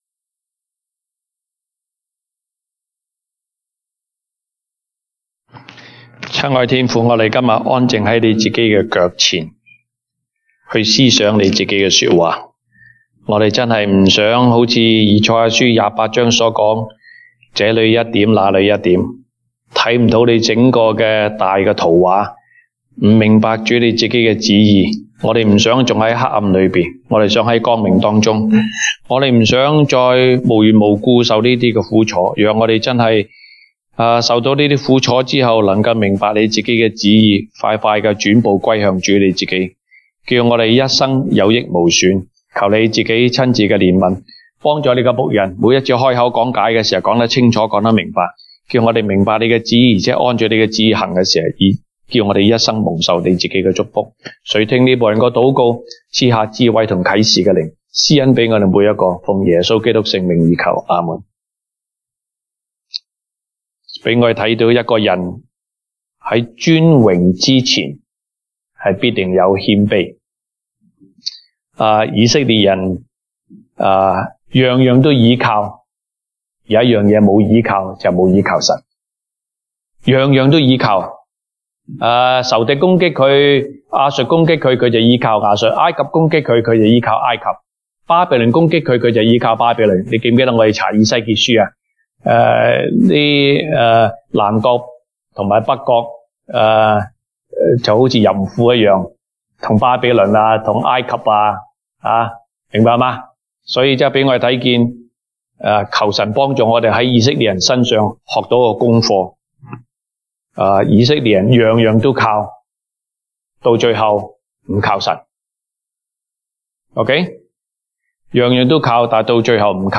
東北堂證道 (粵語) North Side: 艱難的日子: 被神潔淨後，就有祝福。
Isaiah Passage: 以賽亞書 Isaiah 3:1-4:6 Service Type: 東北堂證道 (粵語) North Side (First Church) Topics